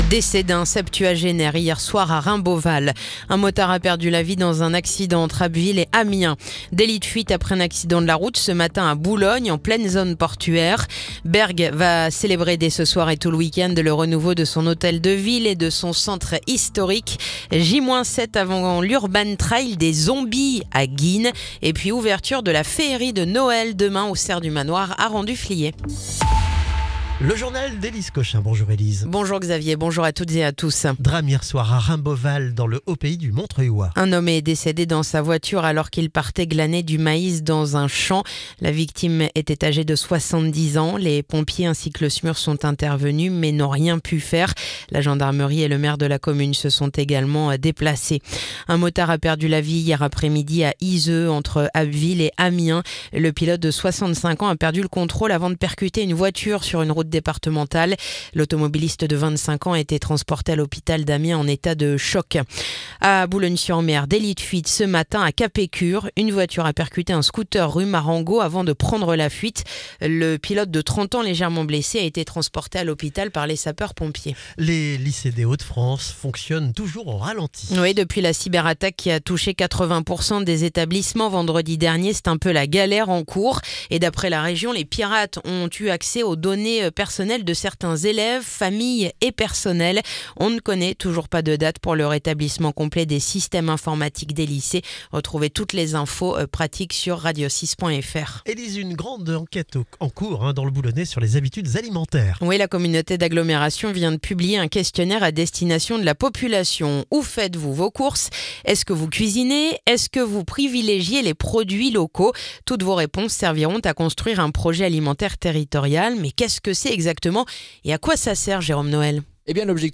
Le journal du vendredi 17 octobre